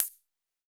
UHH_ElectroHatA_Hit-07.wav